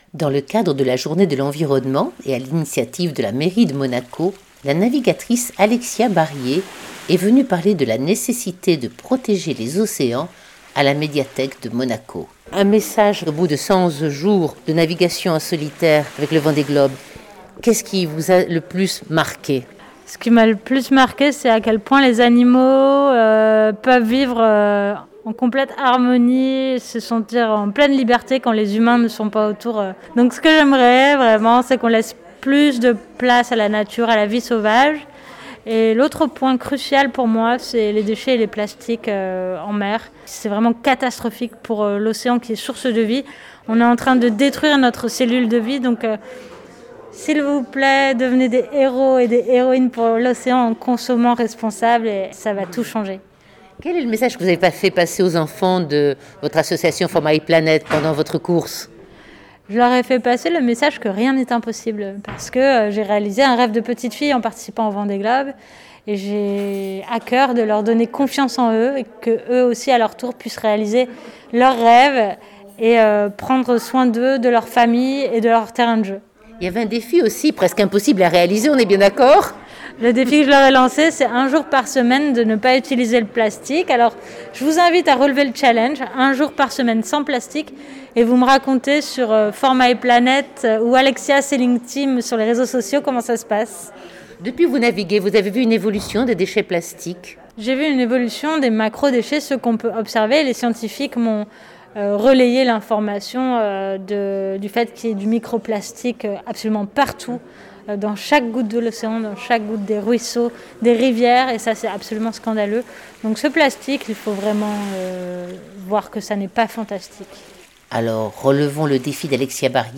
Dans le cadre de la journée de l’environnement et à l’initiative de la Mairie de Monaco, la navigatrice Alexia Barrier est venue parler de la nécessité de protéger les océans à la Médiathèque de Monaco.